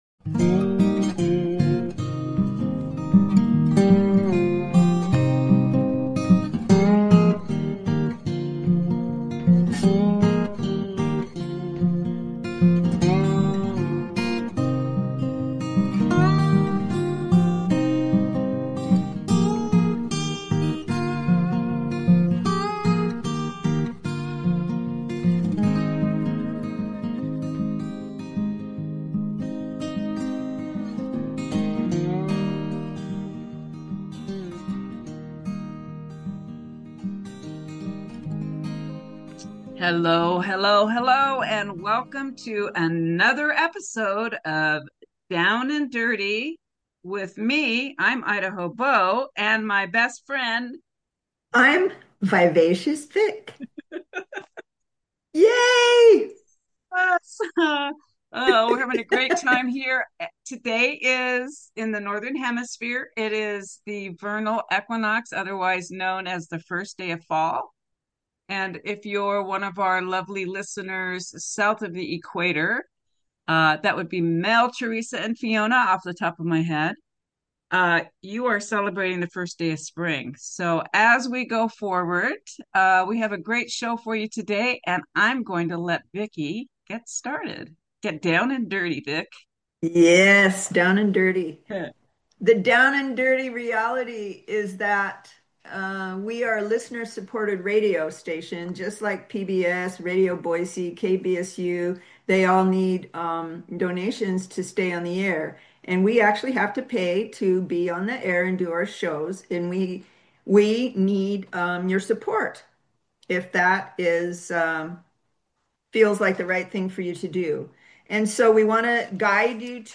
Talk Show Episode, Audio Podcast, Down and Dirty and Seasonal Changes, Gut Health and Boosting Your Immune System at Home on , show guests , about Community,Gardening,Homecare,hobbies,Lifestyle,holistic health,sovereign health,change of Seasons, categorized as Earth & Space,Plant & Animals,Education,Health & Lifestyle,Homeopathy,Nutrition,Self Help,Society and Culture,Spiritual